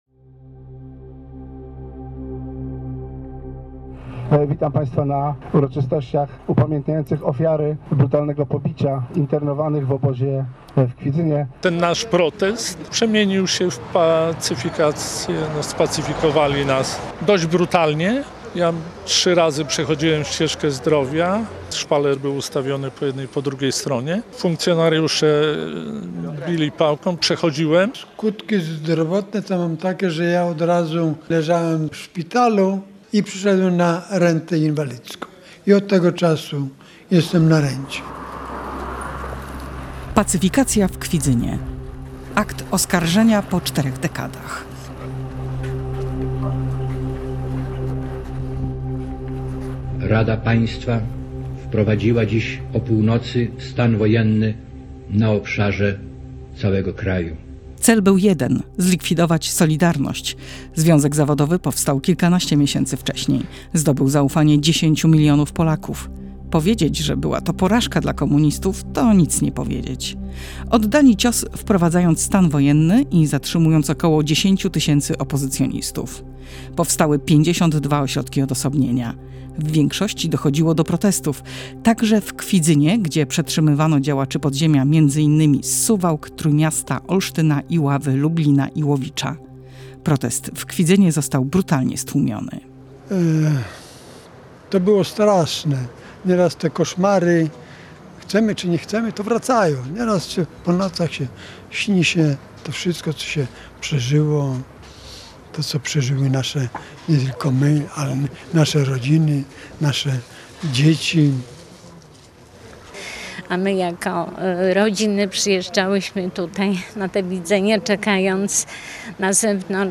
Audycja dokumentalna